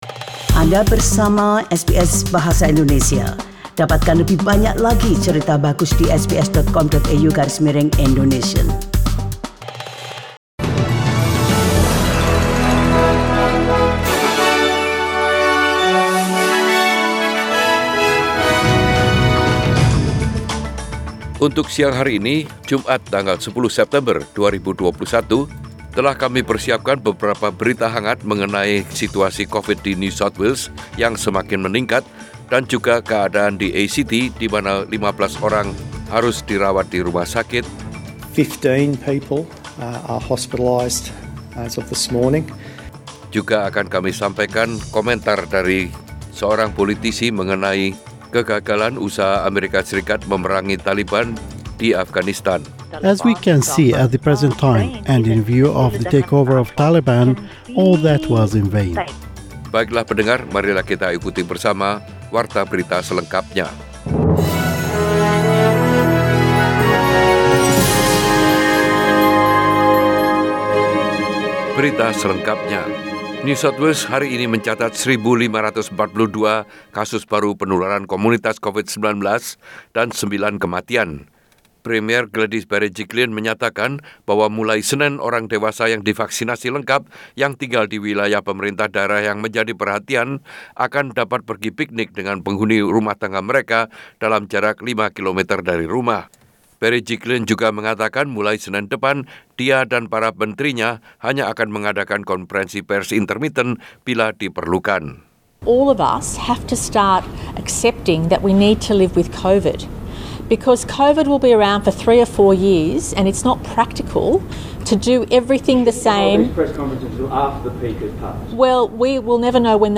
SBS Radio News in Bahasa Indonesia - 10 September 2021
Warta Berita Radio SBS dalam Bahasa Indonesia Source: SBS